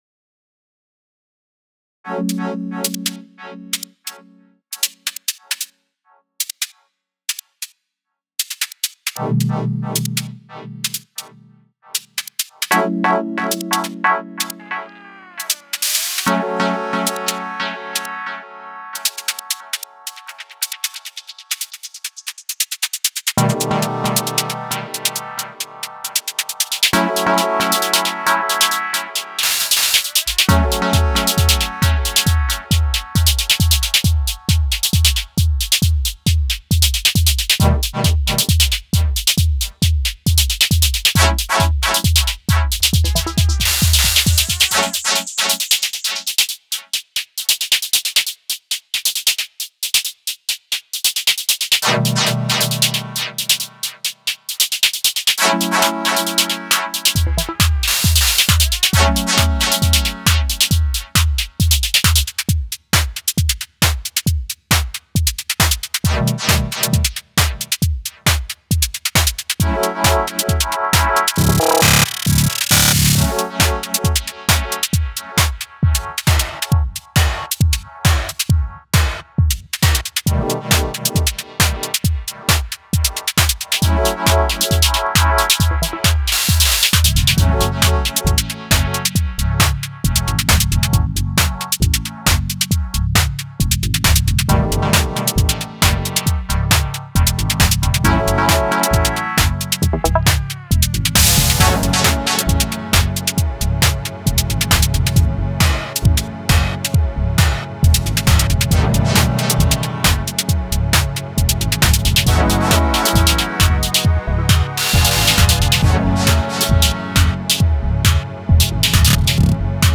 Genre Breakbeat